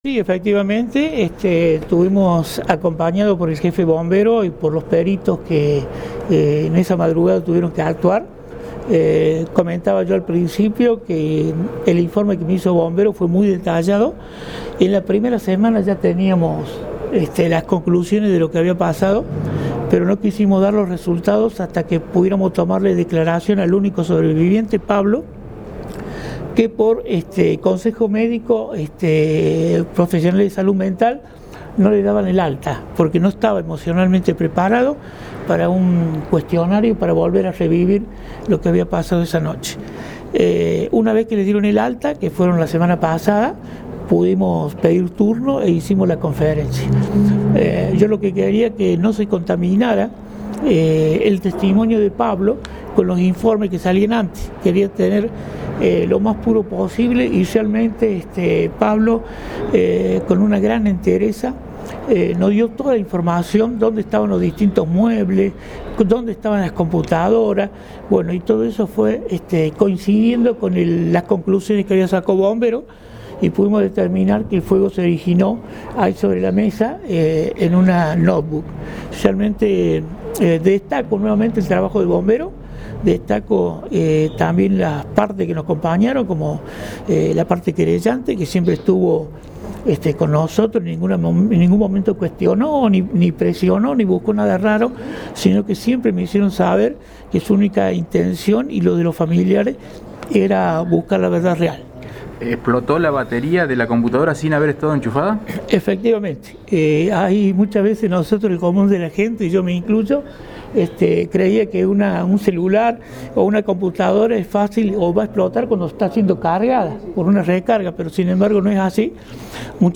Audio: Fiscal Rubén Caro.